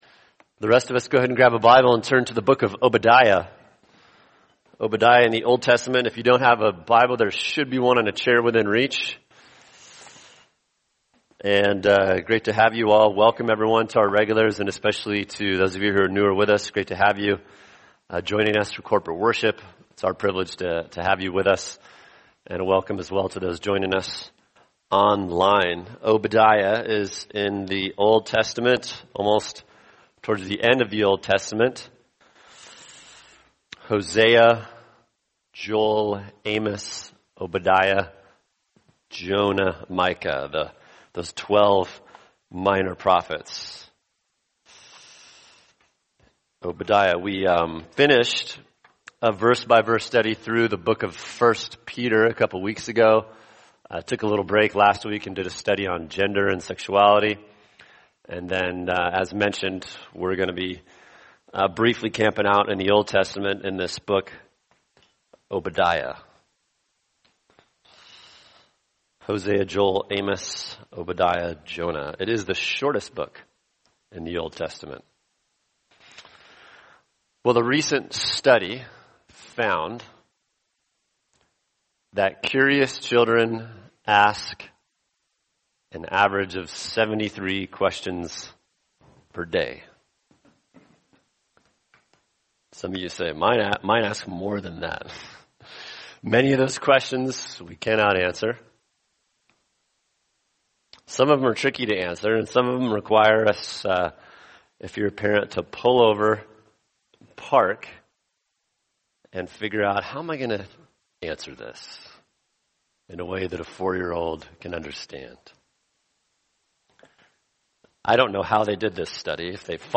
[sermon] Obadiah 1:1-2 God’s Sovereignty and Our Responsibility | Cornerstone Church - Jackson Hole